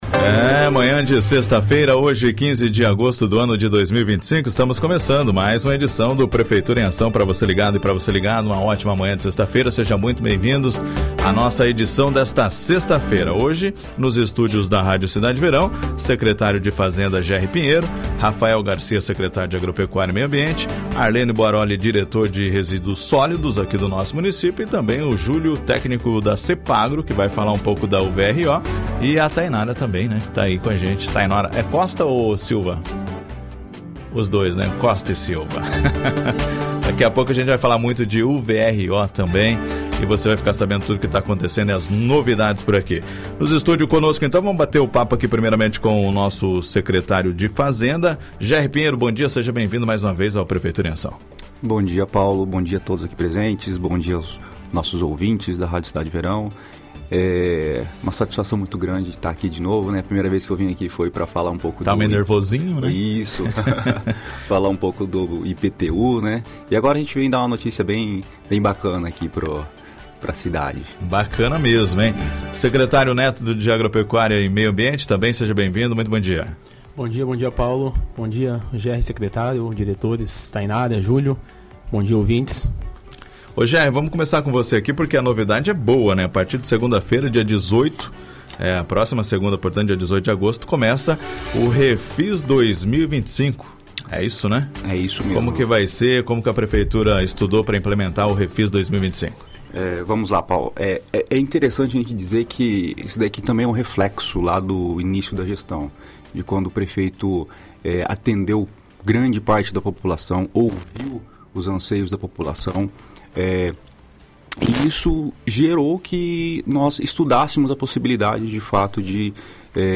Prefeitura em Ação 15/08/2025 ouça a entrevista completa